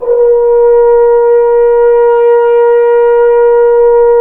Index of /90_sSampleCDs/Roland LCDP12 Solo Brass/BRS_French Horn/BRS_F.Horn 3 pp